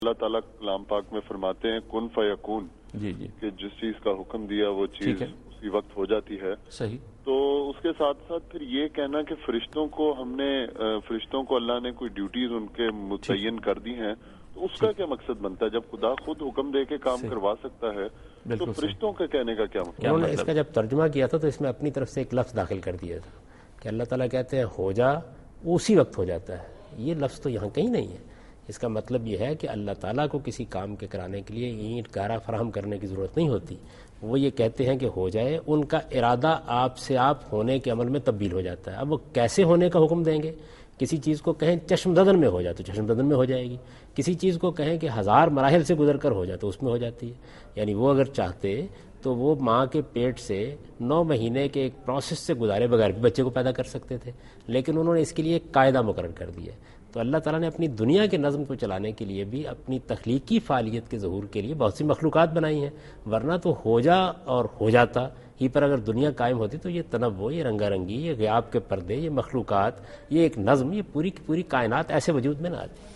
Category: TV Programs / Dunya News / Deen-o-Daanish / Questions_Answers /
دنیا نیوز کے پروگرام دین و دانش میں جاوید احمد غامدی ”کن فیکون‘ کے ترجمے میں غلطی“ سے متعلق ایک سوال کا جواب دے رہے ہیں